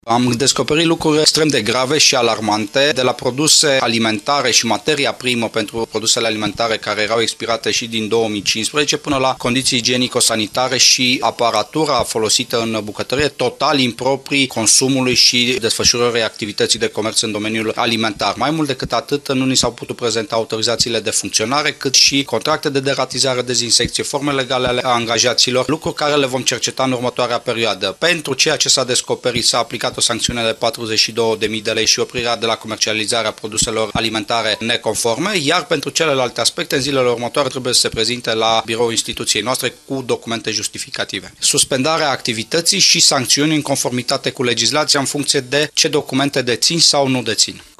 Cam așa arată Popasul Căprioara din Poiana Brașov, verificat de comisarii CRPC, spune șeful instituției, cms.șef Sorin Susanu.